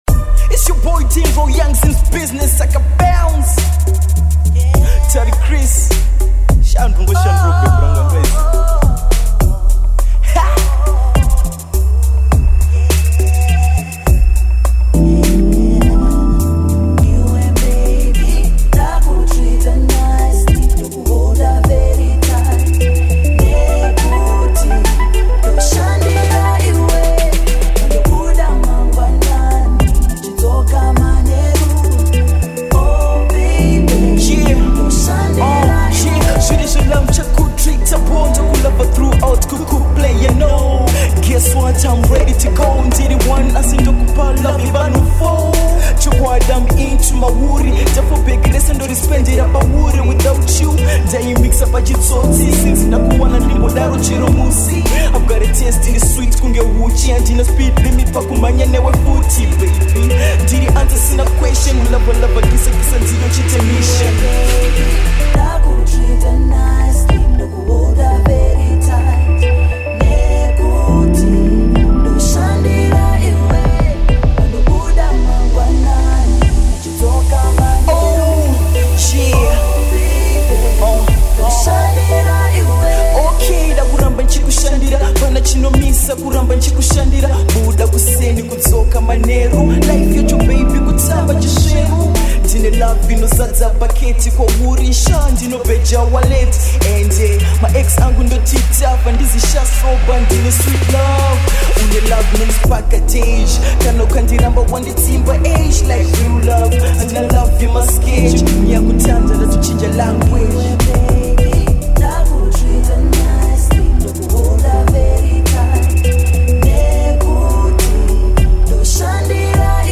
Rap-HipHop